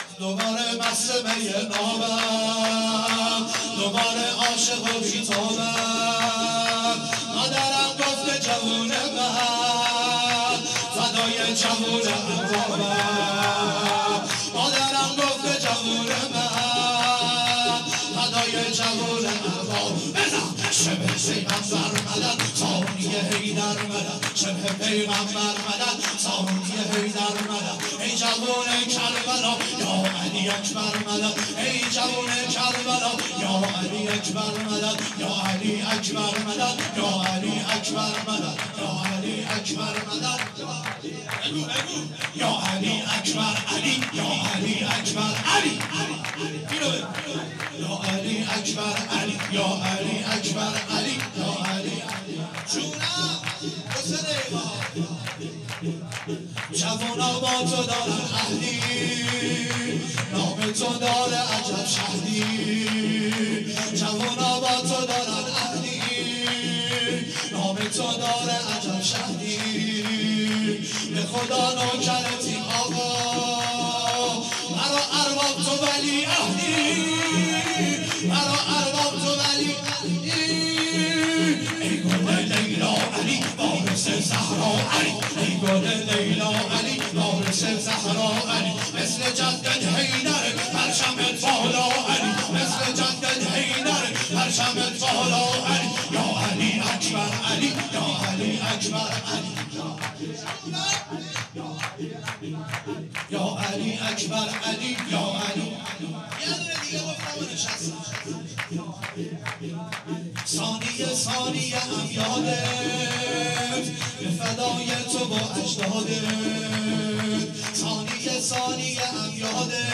جشن ولادت حضرت علی اکبر(ع) ۹۸ شور